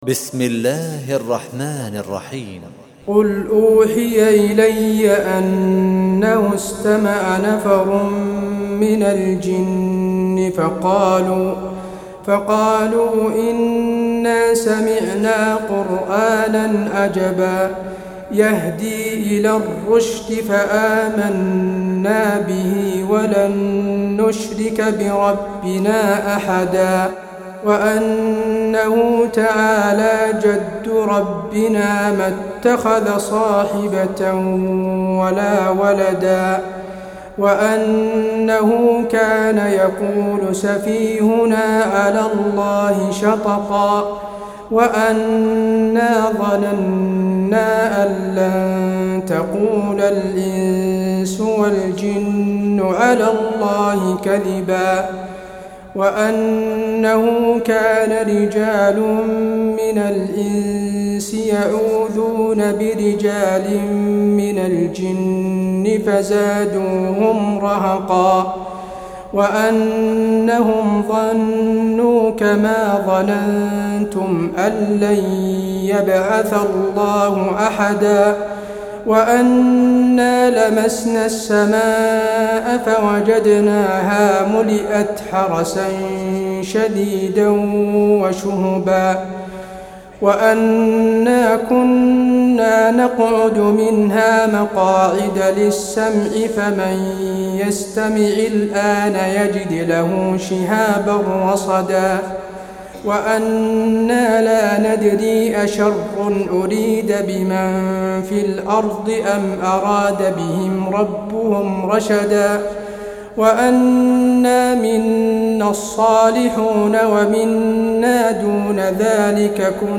Sourate Al Jinn du cheikh tarawih madinah 1427 en mp3, lire et telecharger sourate Al Jinn